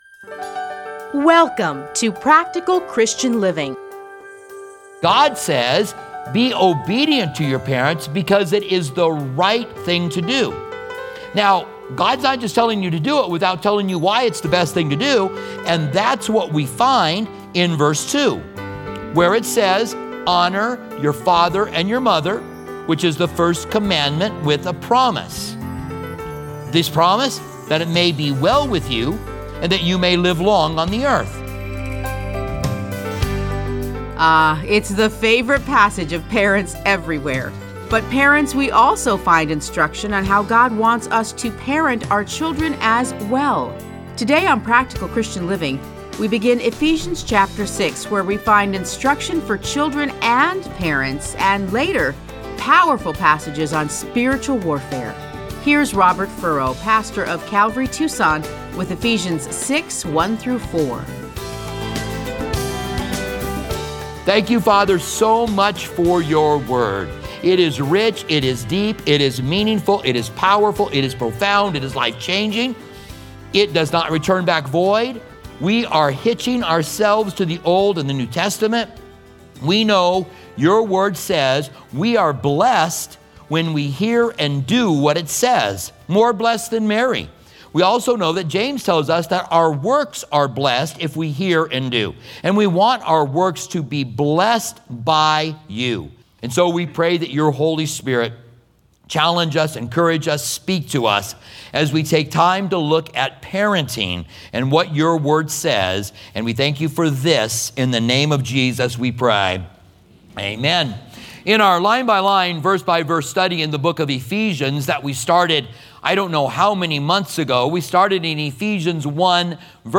Listen to a teaching from Ephesians 6:1-4.